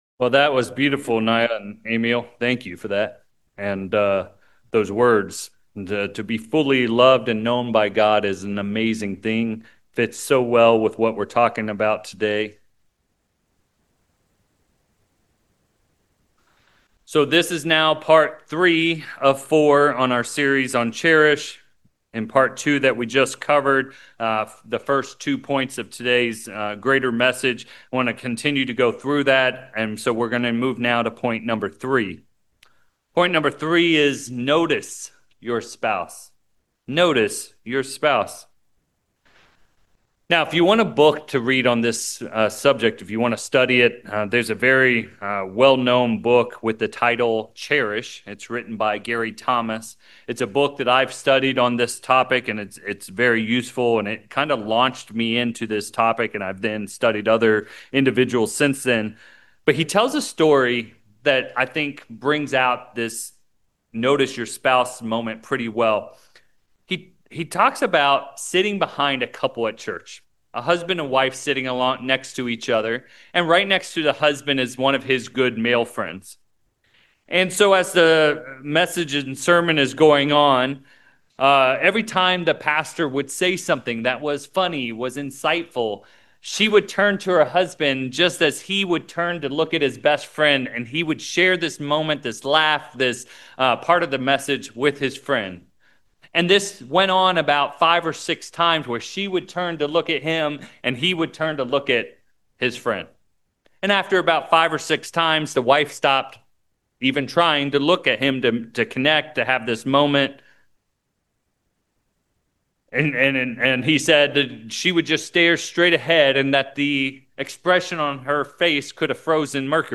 Sermons
Given in San Francisco Bay Area, CA Petaluma, CA San Jose, CA